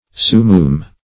sumoom - definition of sumoom - synonyms, pronunciation, spelling from Free Dictionary Search Result for " sumoom" : The Collaborative International Dictionary of English v.0.48: Sumoom \Su*moom"\, n. See Simoom .